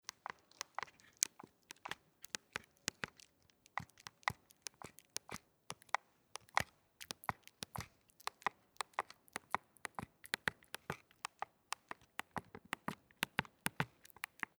Tasten drücken
1316_Tasten_druecken.mp3